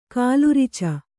♪ kālurica